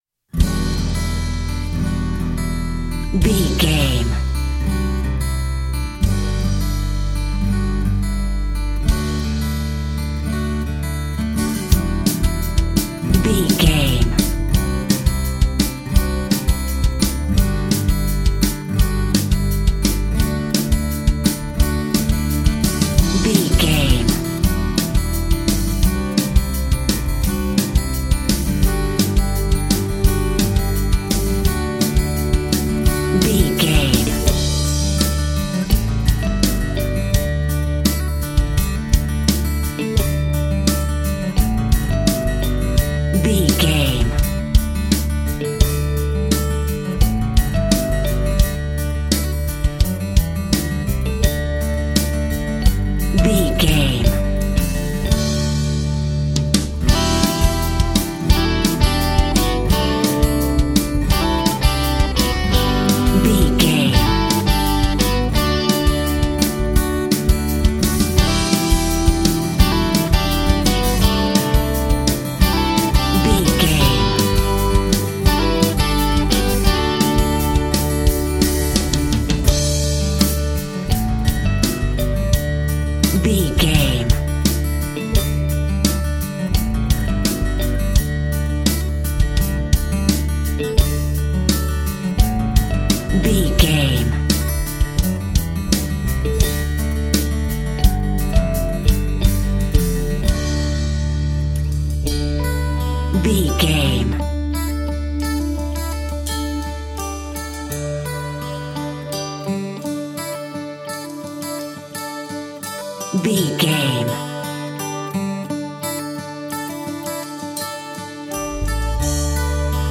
Uplifting
Aeolian/Minor
acoustic guitar
dreamy
relaxed
tranquil
soft
hopeful
peaceful